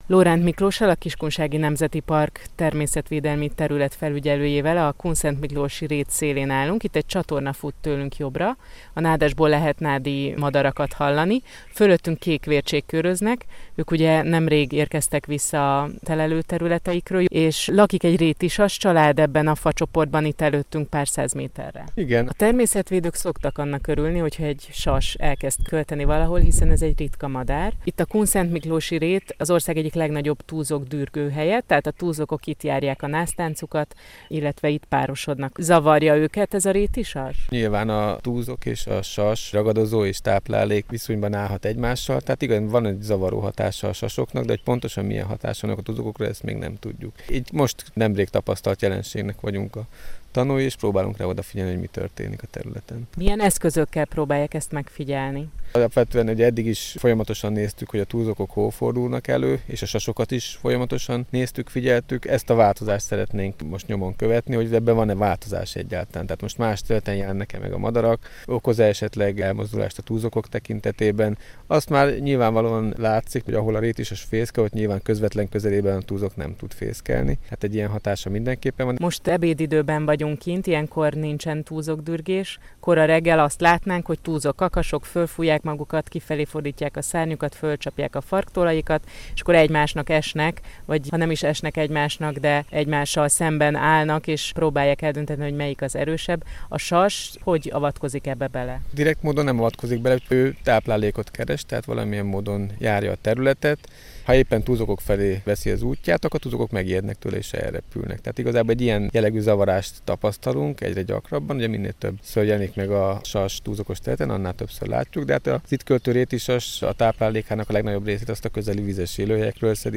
Radio Post MR1 Kossuth from 10.02.2017